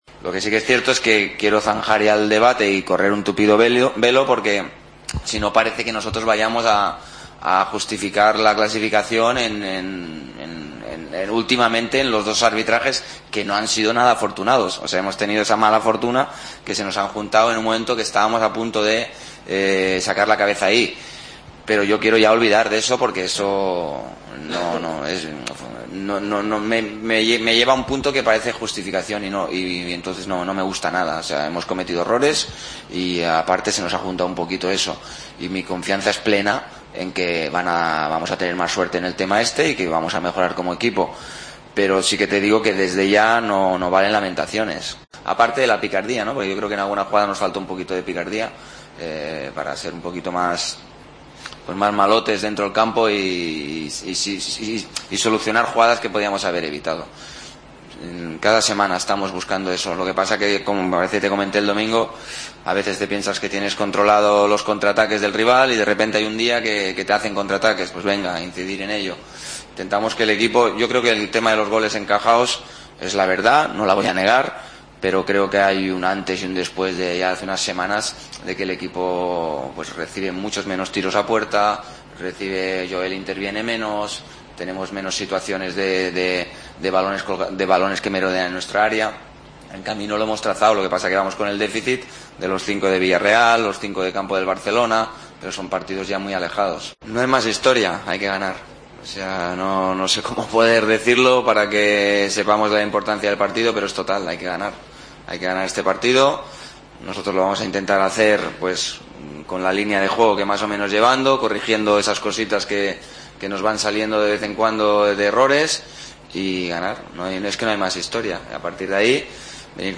Rubi, en la rueda de prensa previa al partido ante el Leganés